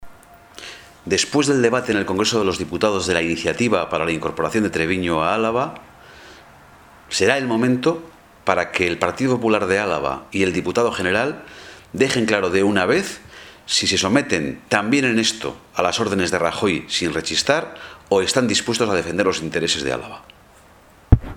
• Ramiro González sobre la incorporación de Trebiño a Araba 07/11/2014